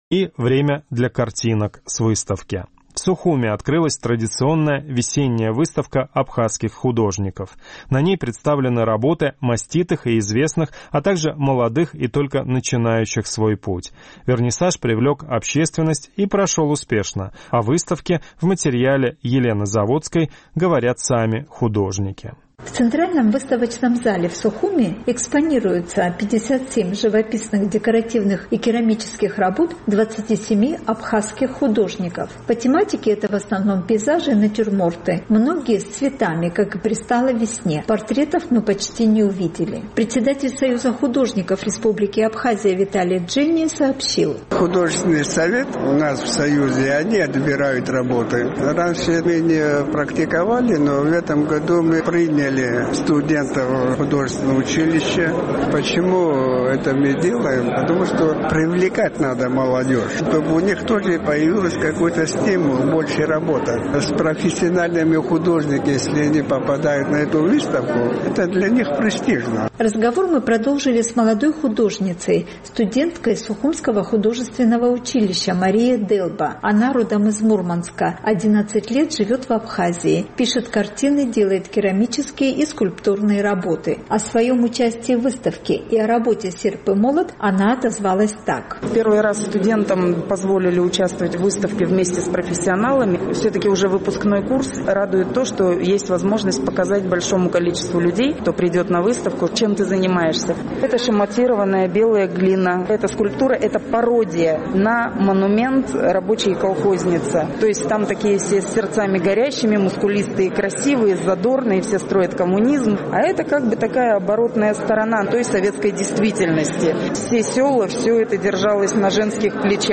О выставке и о себе говорят сами художники.